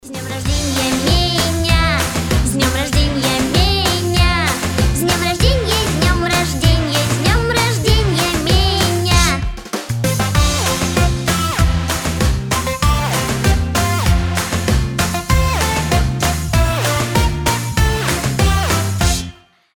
Рингтоны без слов , Саксофон
Инструментальные